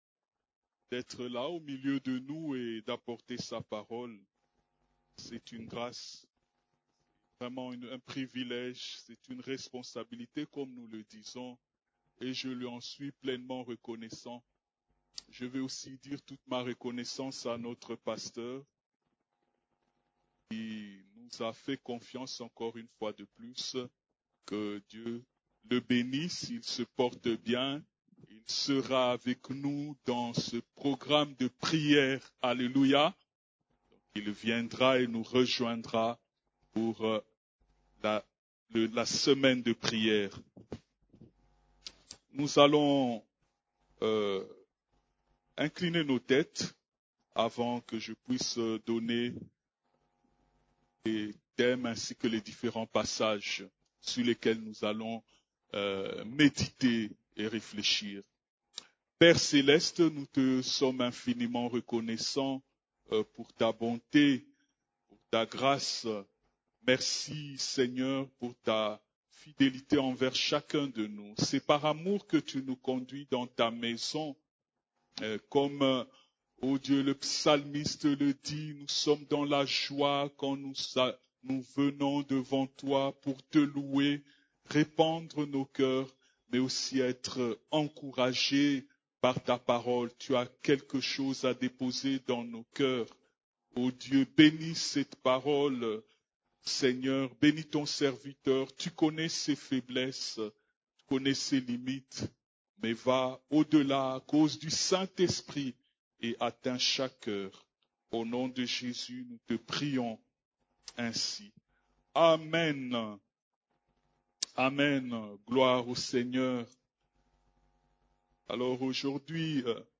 CEF la Borne, Culte du Dimanche, Comment voir l'invisible ?